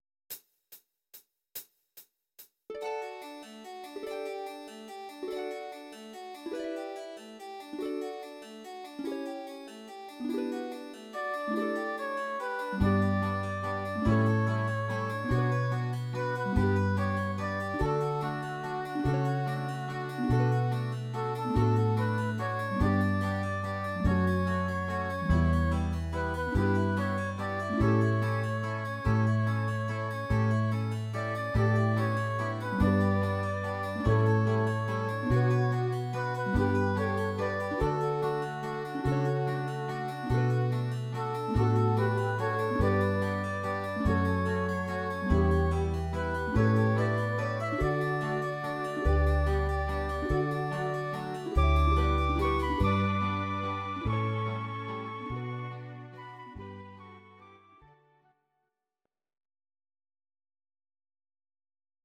These are MP3 versions of our MIDI file catalogue.
Please note: no vocals and no karaoke included.
(Instrumental)